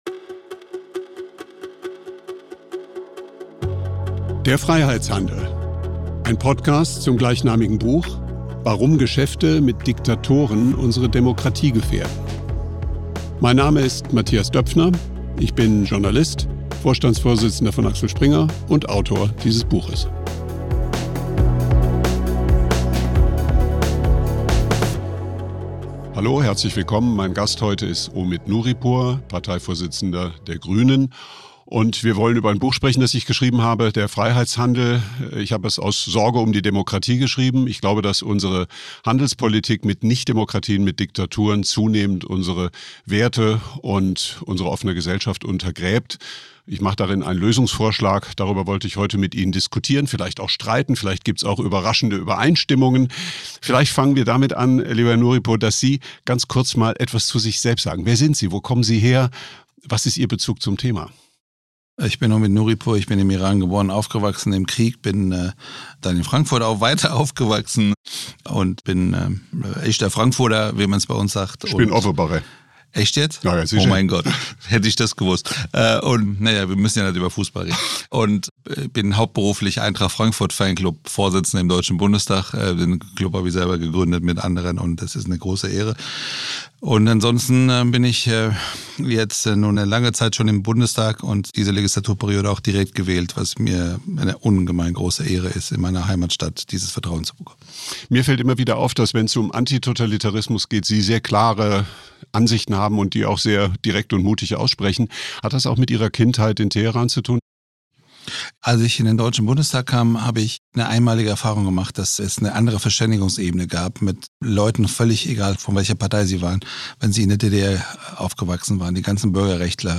Omid Nouripour und Mathias Döpfner im Dialog zu „Der Freiheitshandel“ ~ Der Freiheitshandel Podcast
In der zweiten Folge von „Der Freiheitshandel“ begrüßt Mathias Döpfner den Parteivorsitzenden der Grünen, Omid Nouripour, zu einer Diskussion über die zerbrechliche Natur der Freiheit in der heutigen Welt.